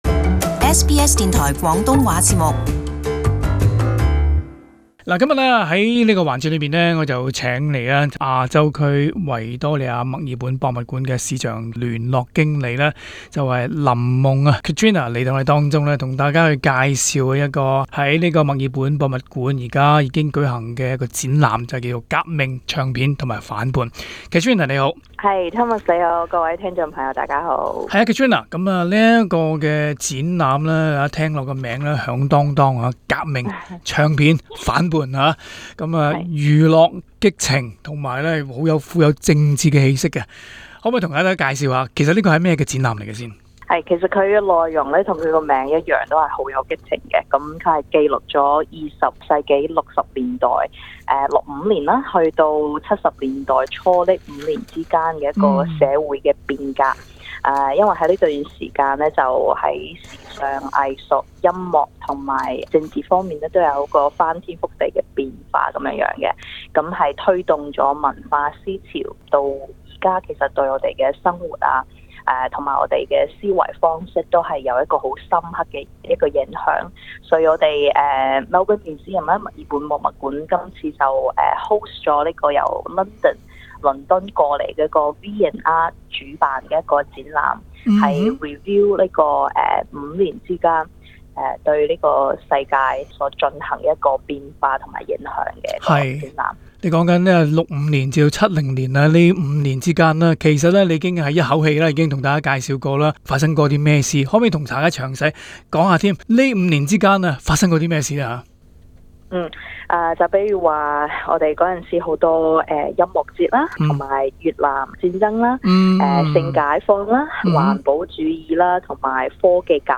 【社团专访】革命、唱片、反叛